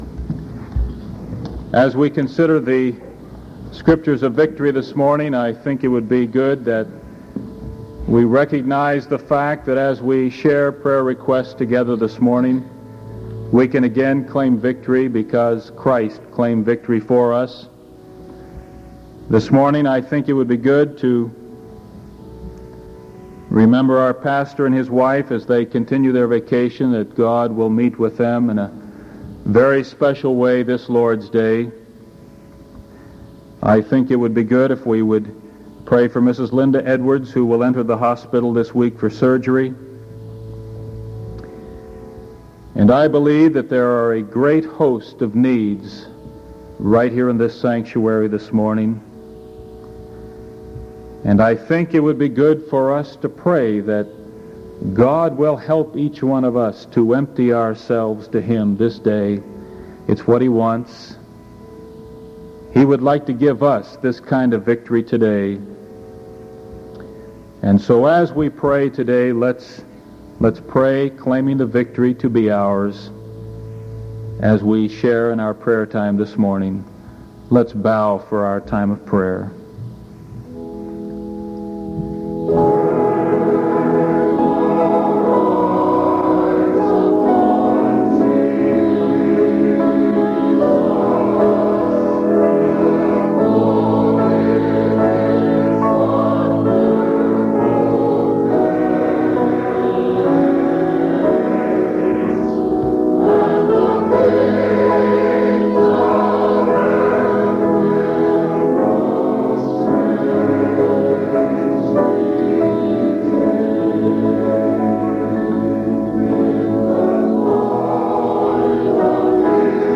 Sermon July 22nd 1973 AM